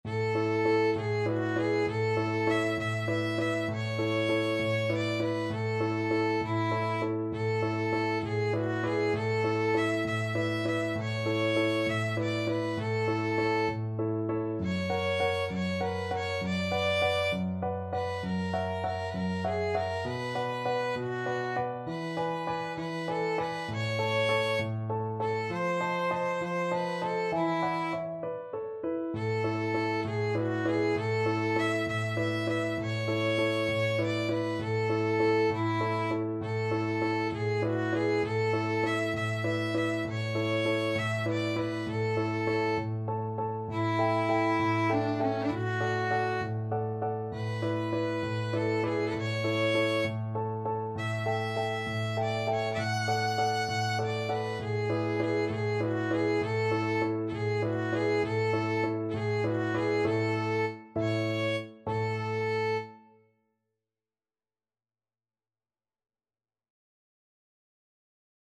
Violin
6/8 (View more 6/8 Music)
. = 66 No. 3 Grazioso
A major (Sounding Pitch) (View more A major Music for Violin )
Classical (View more Classical Violin Music)
mauro_giuliani_op74_3_VLN.mp3